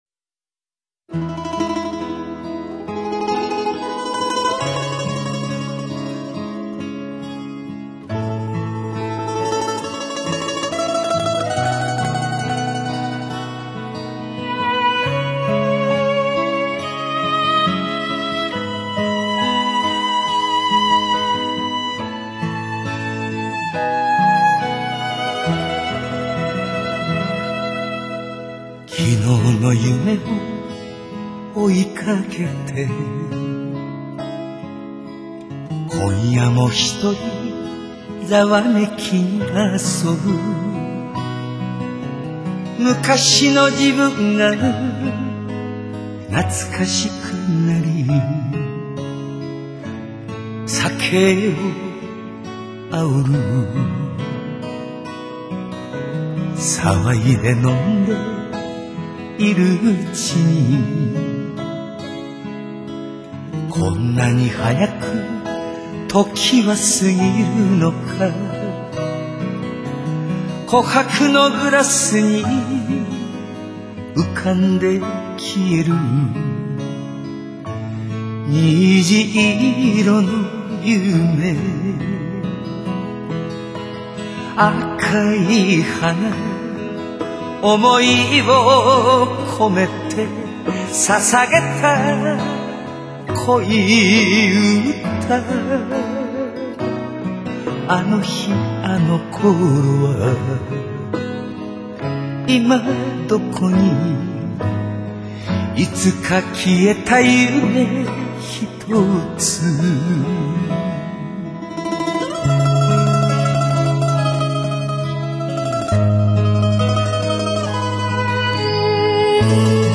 犹如自语般的旋律里充满了人生哀伤感的诗的世界。